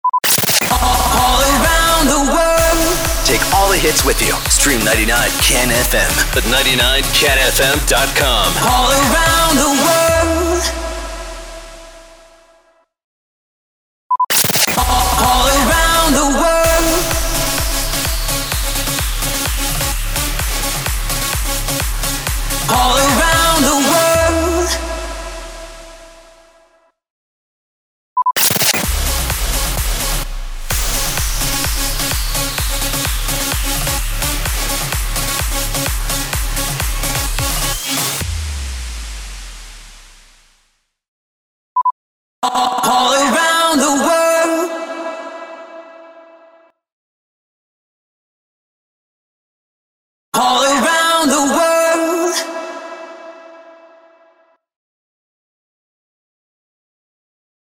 764 – SWEEPER – WEBSITE
764-SWEEPER-WEBSITE.mp3